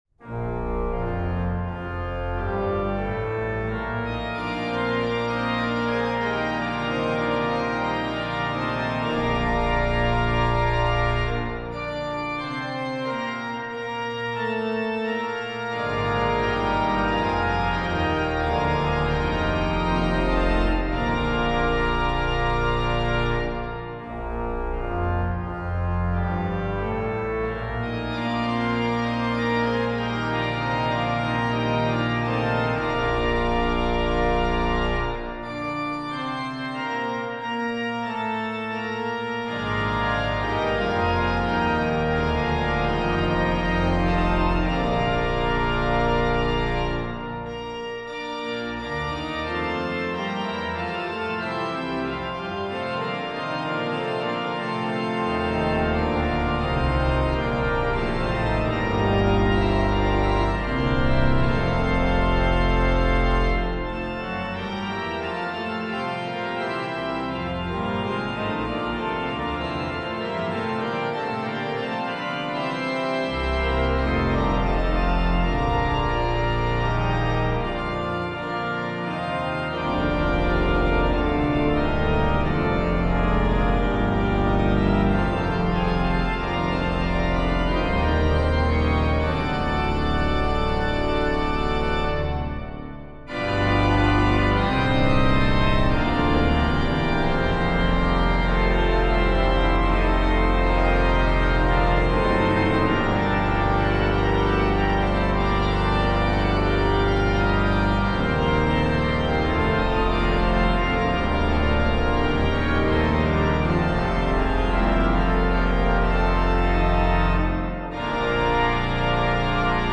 Voicing: Org 2-staff